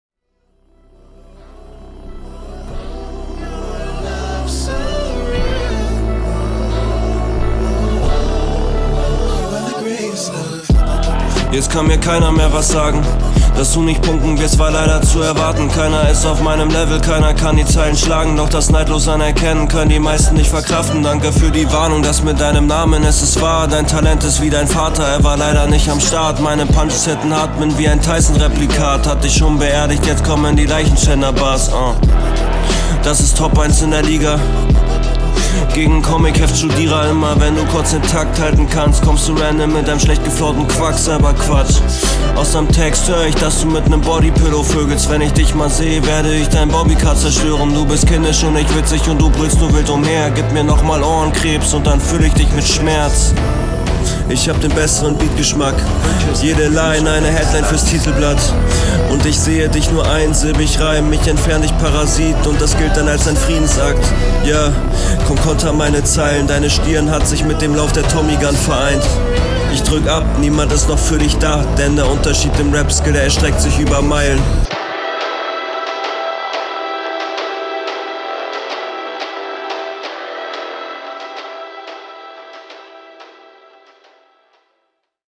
Yea für mein Geschmack geht einfach betonungstechnisch mehr um akzente zu setzen. insgesamt finde ich …